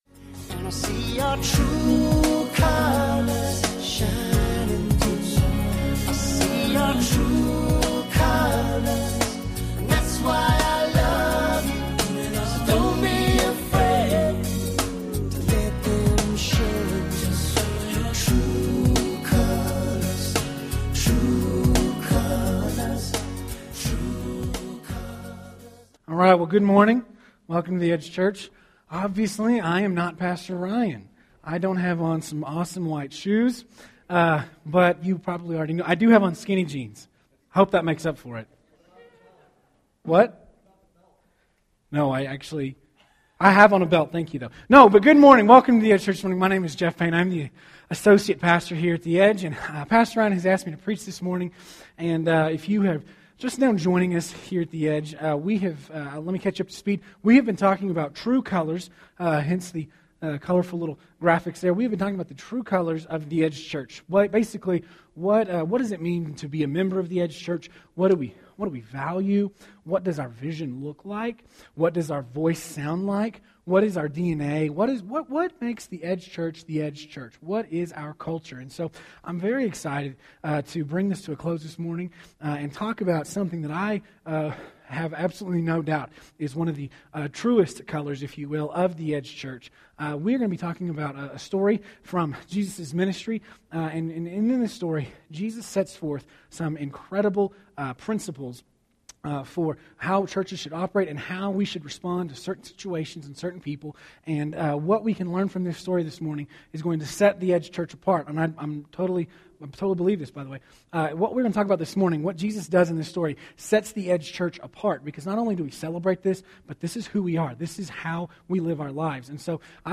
True Colors: Church Of The Second Chance – John 8 – Sermon Sidekick